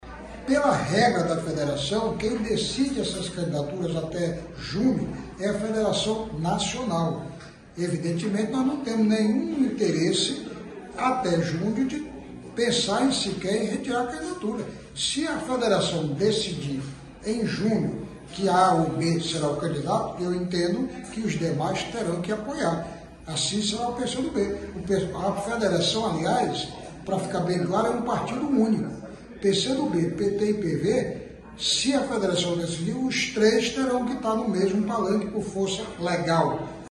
Em declaração a BandNews Difusora FM, o pré-candidato a prefeito Eron Bezerra, esclareceu que, com a decisão, o grupo político passa a ter dois pré-candidatos.